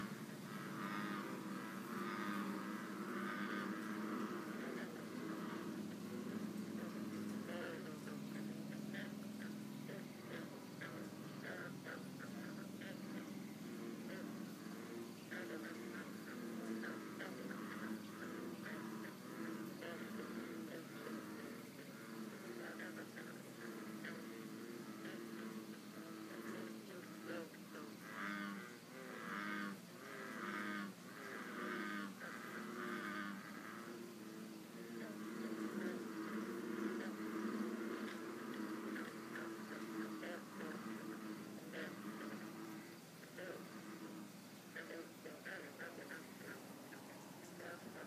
a froggy chorus
Anyway, I think he’s the king of this group of frogs and he’s very noisy.
As my luck would have it, the frogs sing a lot in the evening … anywhere from 10 p.m. or so all the way until the sun starts rising again.
froggy chorus (You may have to turn up you sound to hear it best, but I think The King pipes in at 0:28 and then all of his buddies shortly thereafter in response).